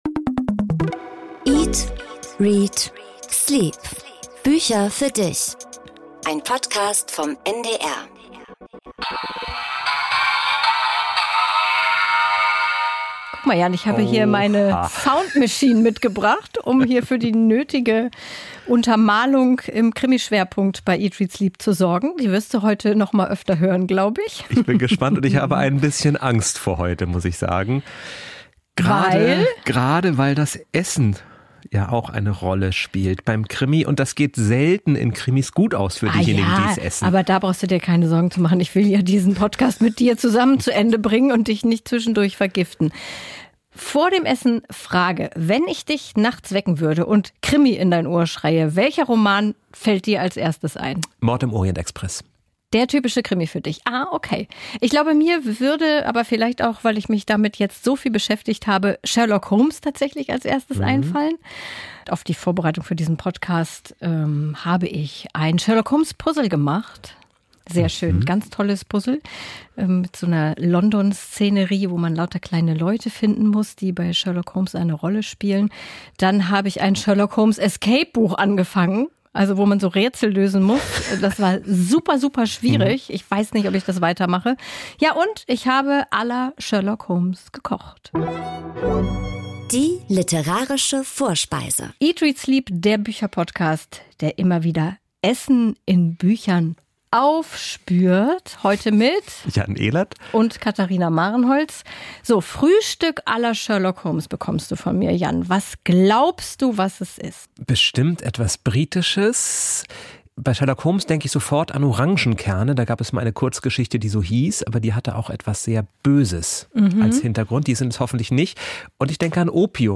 Crime Time im Podcast-Studio:
Interview